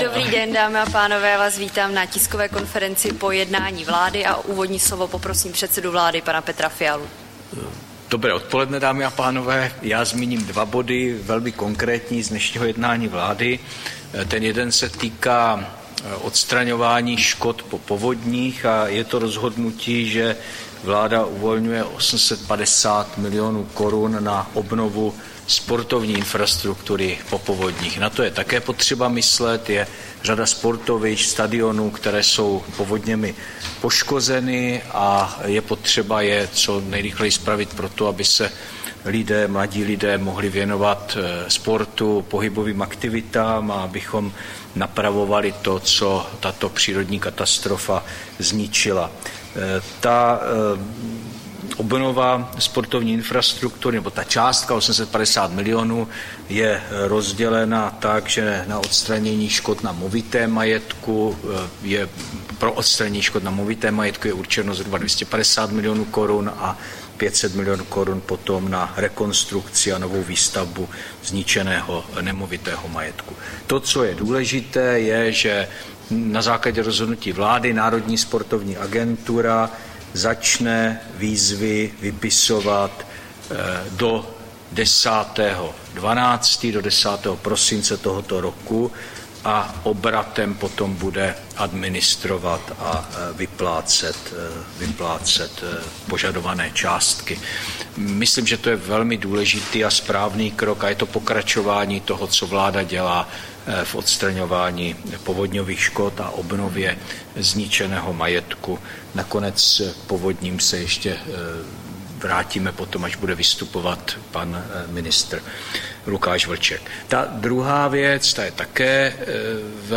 Tisková konference po jednání vlády, 20. listopadu 2024